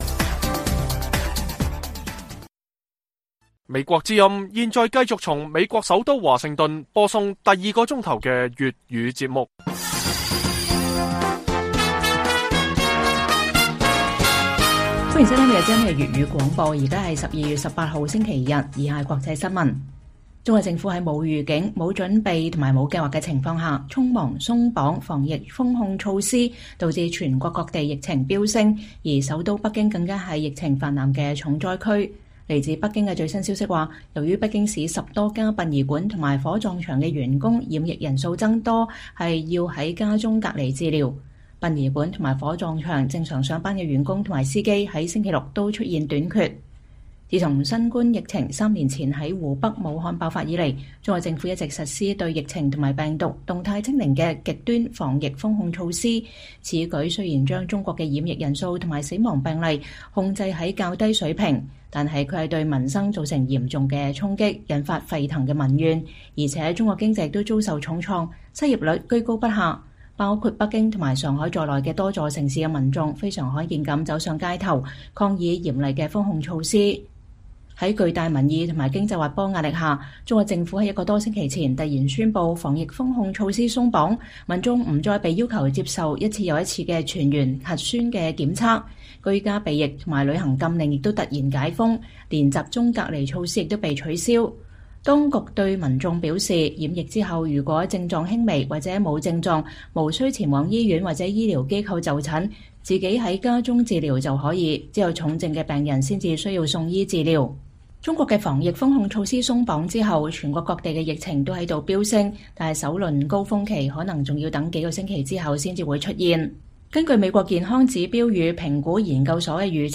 粵語新聞 晚上10-11點：北京疫情肆虐重創服務業，殯儀館人手短缺難以為繼